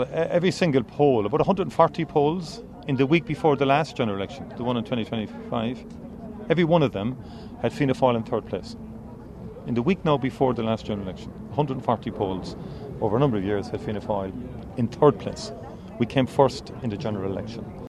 Speaking today, he commented on opinion polls from previous elections, following the latest Irish Times survey.